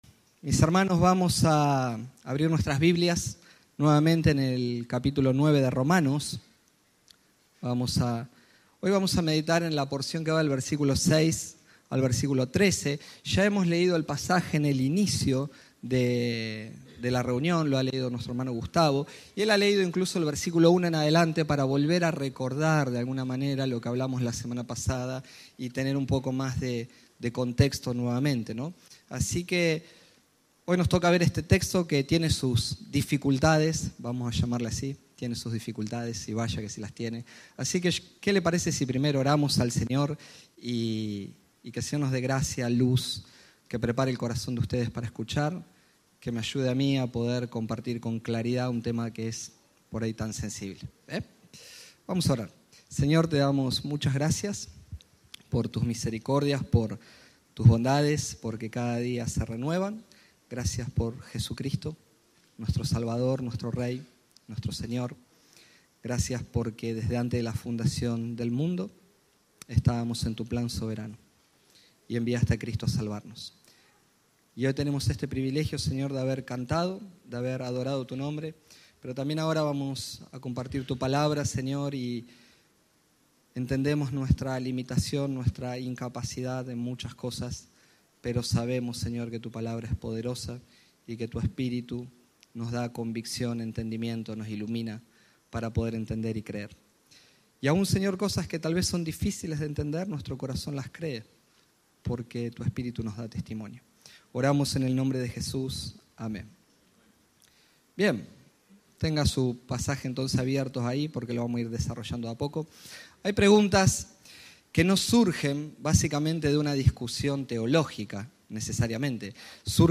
El pastor explica en Romanos 9 que la salvación depende de la elección soberana de Dios, no de esfuerzos humanos ni descendencia.